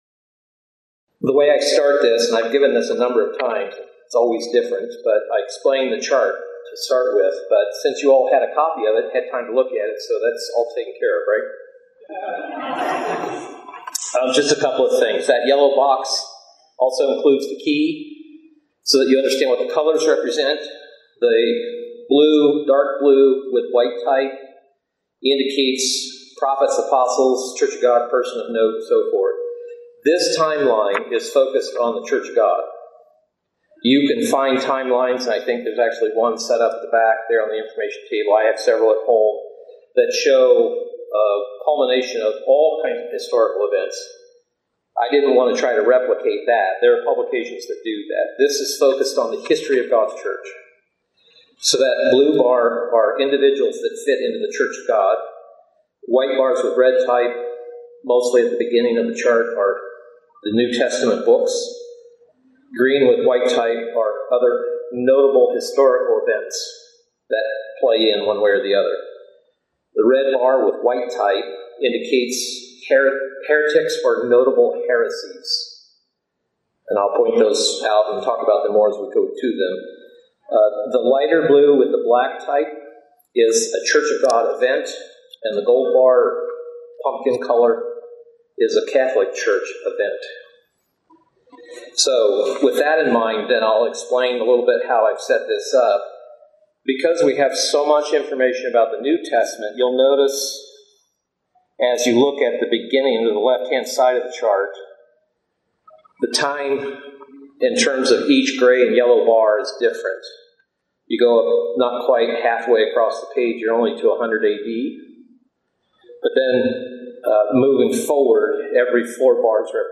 Bible Study, The History of the True Church since 31AD (NB FT)
Given in Houston, TX